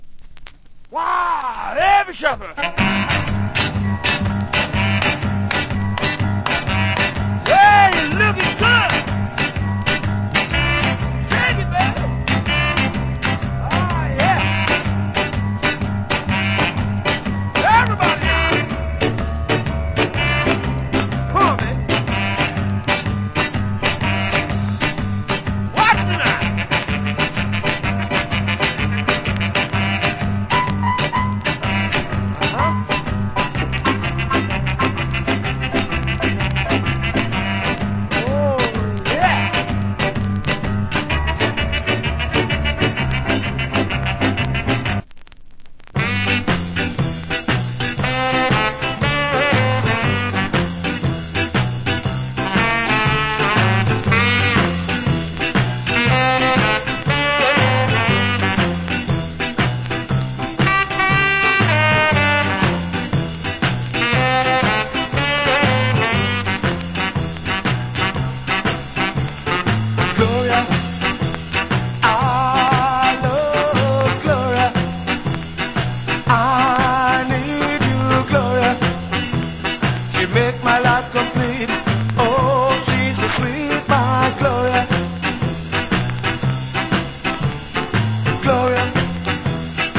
WIcked UK Ska Inst.